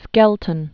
(skĕltən), John 1460?-1529.